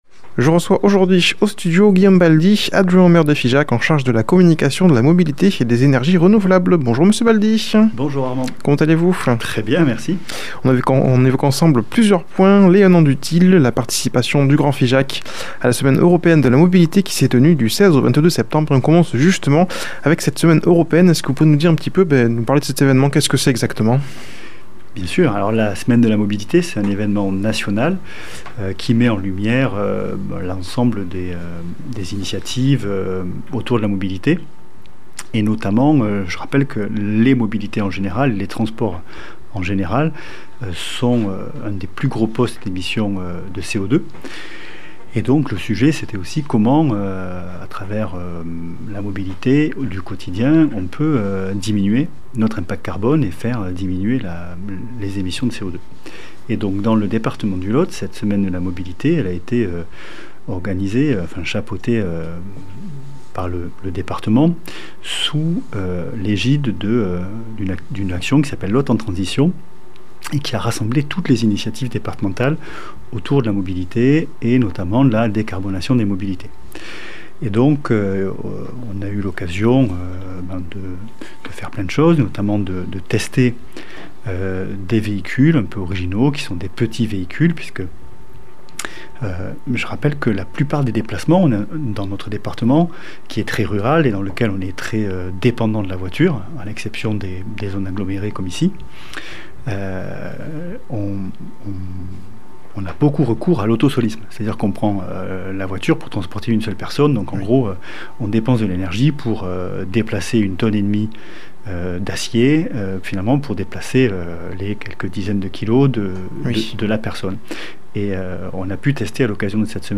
a comme invité au studio Guillaume Baldy adjoint au maire de Figeac en charge de la communication, de la mobilité et des énergies renouvelables. Il vient évoquer les 1 an du TIL et la semaine européenne de la mobilité qui s'est tenue du 16 au 22 Septembre dernier